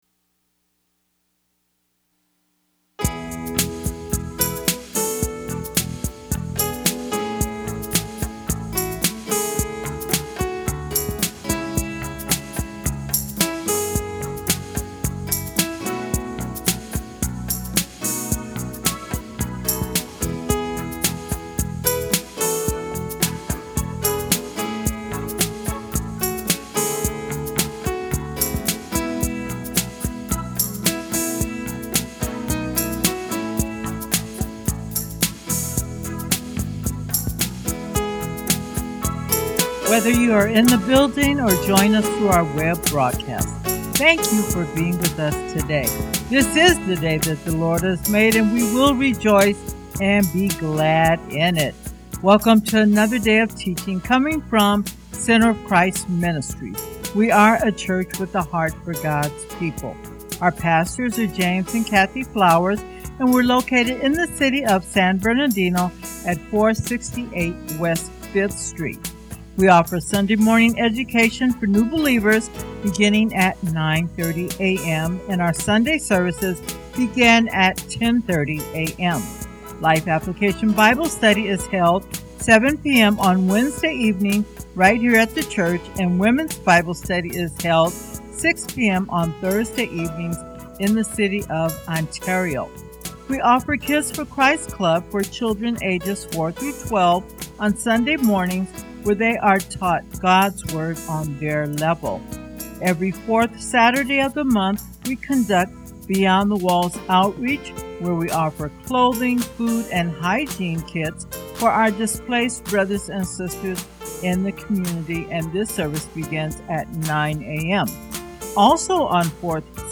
Welcome to episode 25 of “The Love Lifestyle” recorded live at Center Of Christ Ministries.